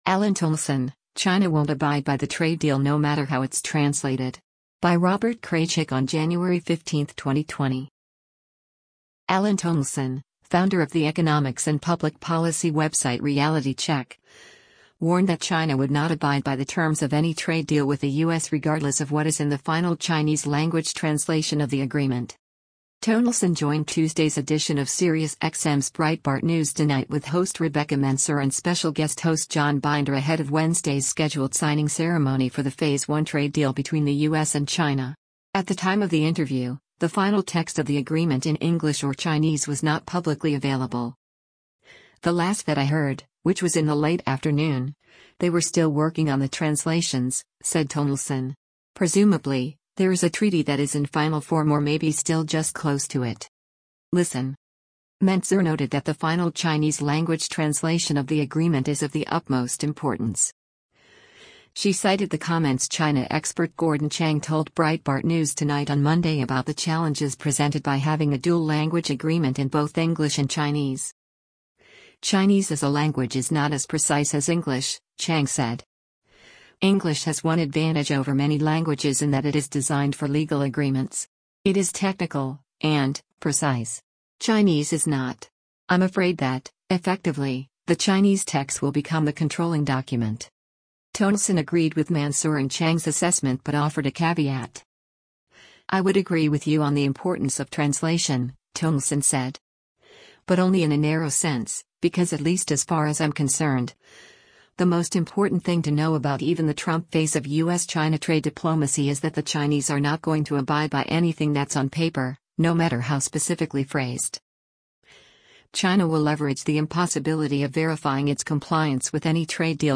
Breitbart News Tonight broadcasts live on SiriusXM Patriot channel 125 weeknights from 9:00 p.m. to midnight Eastern or 6:00 p.m. to 9:00 p.m. Pacific.